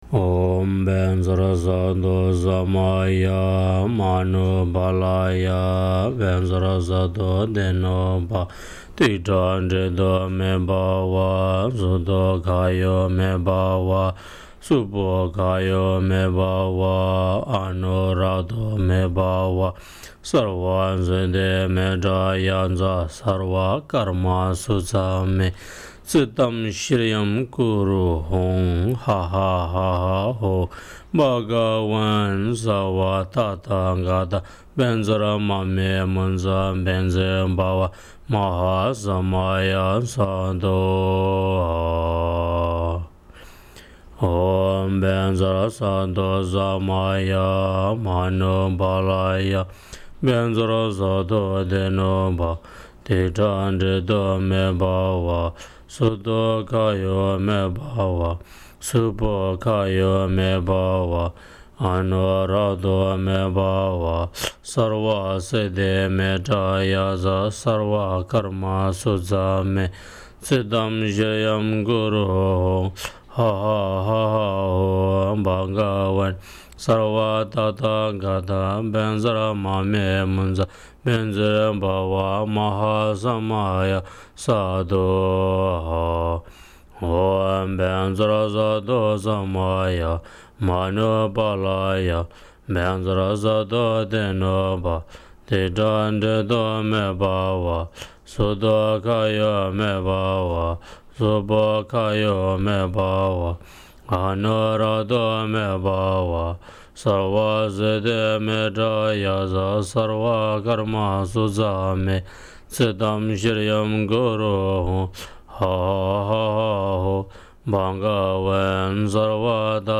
Стослоговая мантра Ваджрасаттвы (mp3 4,01 Mb. 2:11). Чантинг.